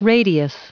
Prononciation du mot radius en anglais (fichier audio)
Prononciation du mot : radius